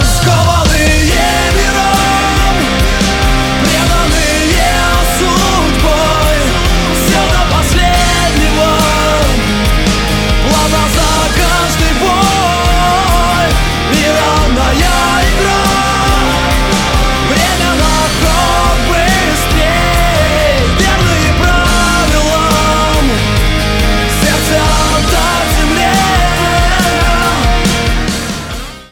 • Качество: 192, Stereo
Рок-группа из Кургана исполнила эту песню.